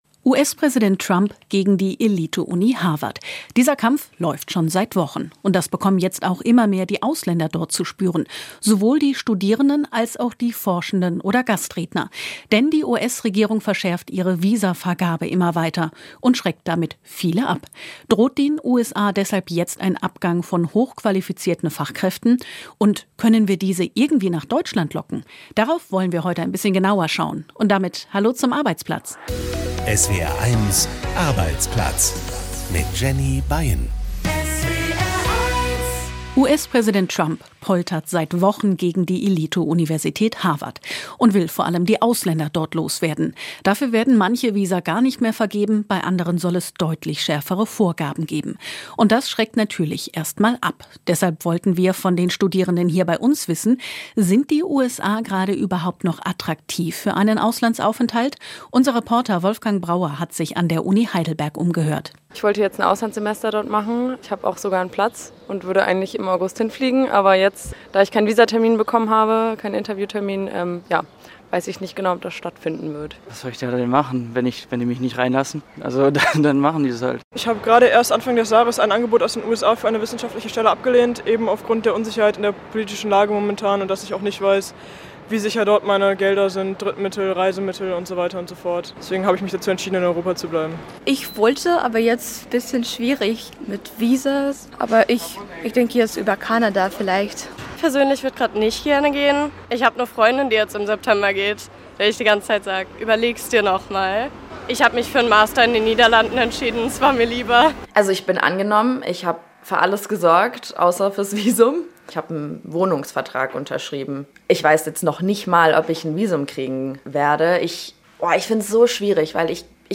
Das Radiomagazin für Arbeitnehmer und Arbeitgeber, für Betroffene und Beobachter, für Eltern und Lehrer, Auszubildende und Ausbilder. Reportagen aus Betrieben, Hintergründe, Meinungen, Urteile und Interviews.